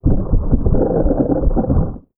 ALIEN_Communication_32_mono.wav